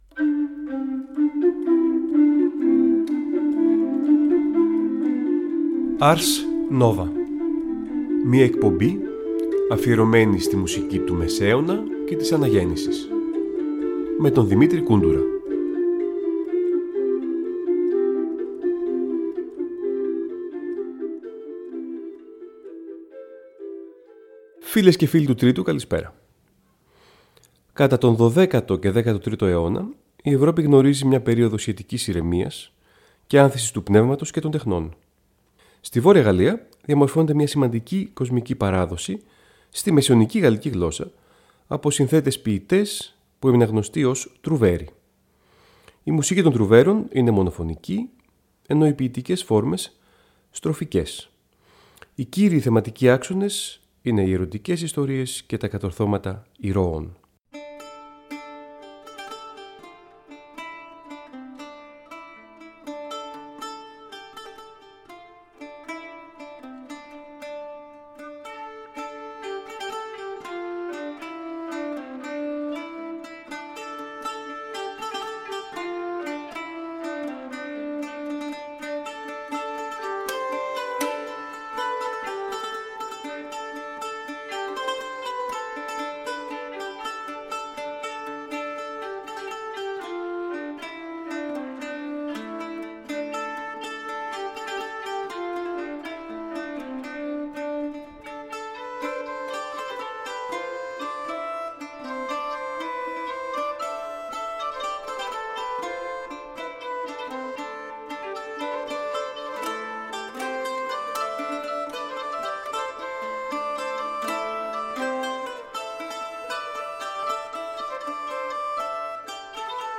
Νέα ωριαία μουσική εκπομπή του Τρίτου Προγράμματος που μεταδίδεται κάθε Τρίτη στις 19:00.